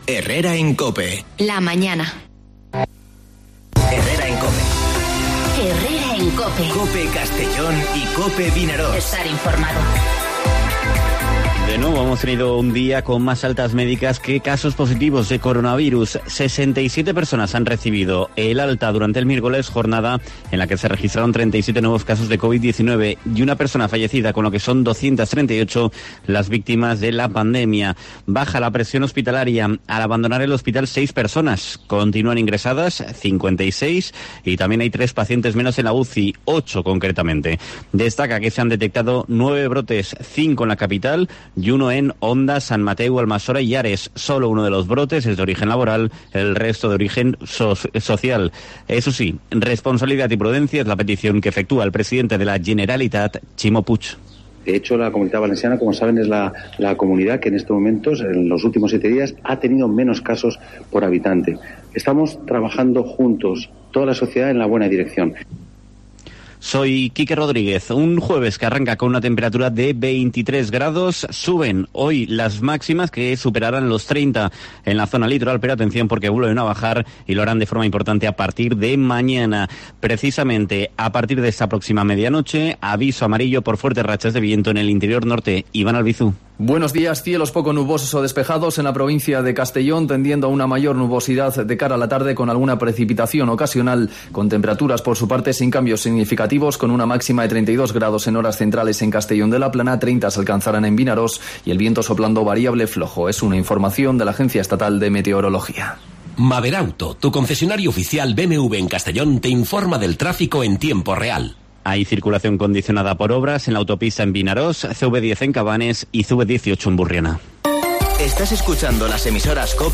Informativo Herrera en COPE en la provincia de Castellón (24/09/2020)